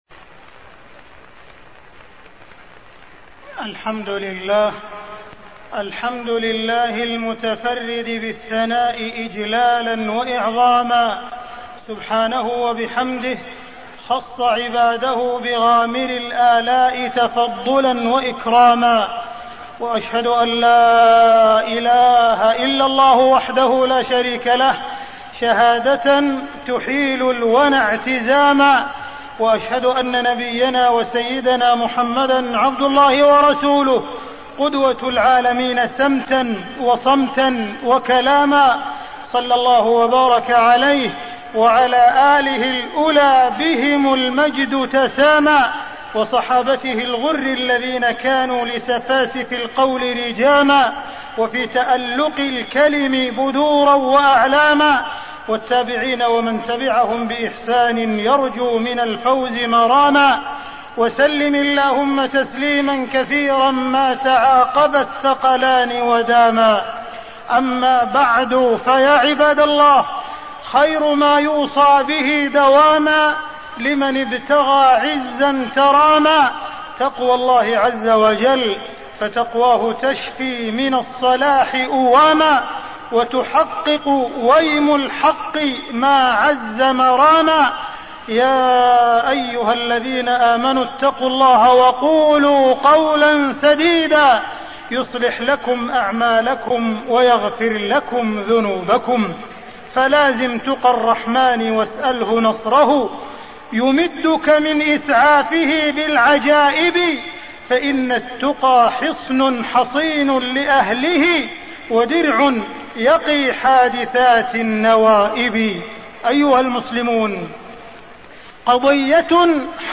تاريخ النشر ١٠ ربيع الثاني ١٤٣١ هـ المكان: المسجد الحرام الشيخ: معالي الشيخ أ.د. عبدالرحمن بن عبدالعزيز السديس معالي الشيخ أ.د. عبدالرحمن بن عبدالعزيز السديس خطورة الكلمة